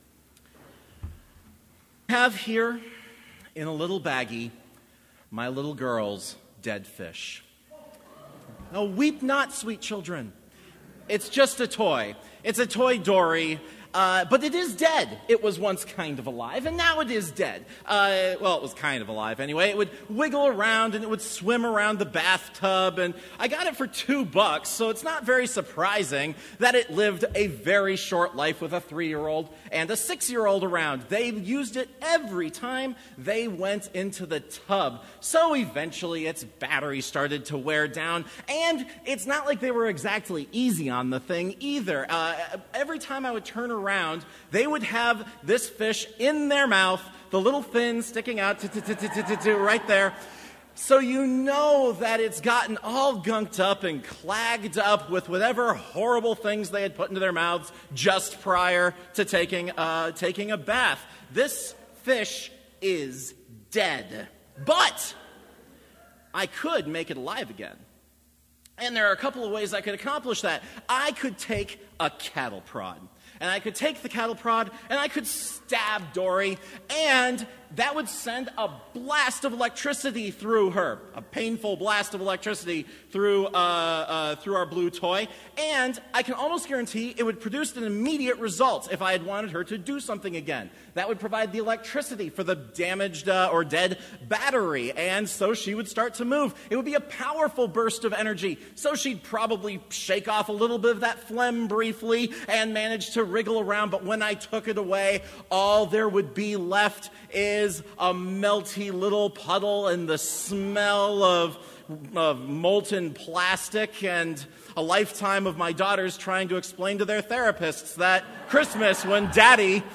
Sermon audio for Chapel - December 13, 2018